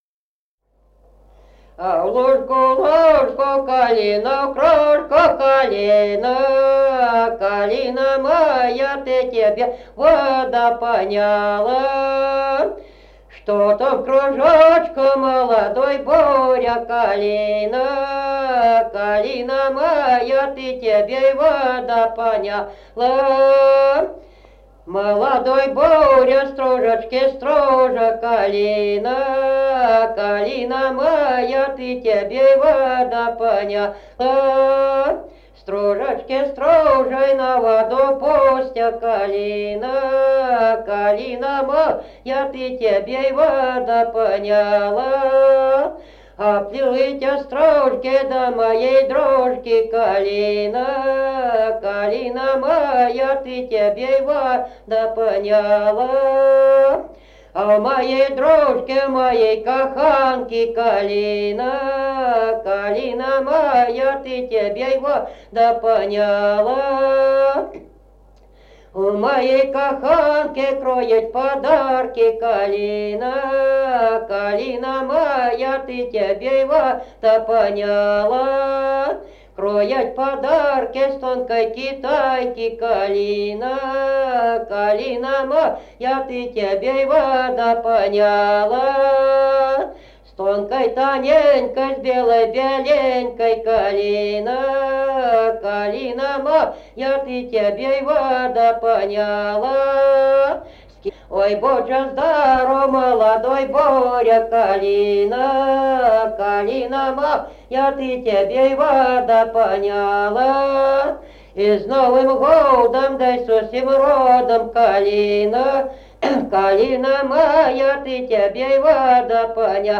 Народные песни Стародубского района «А в лужку, лужку», новогодняя щедровная.
д. Камень.